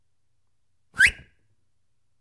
cartoon cartoon-sound comic fx humor slip sound-effect sound effect free sound royalty free Funny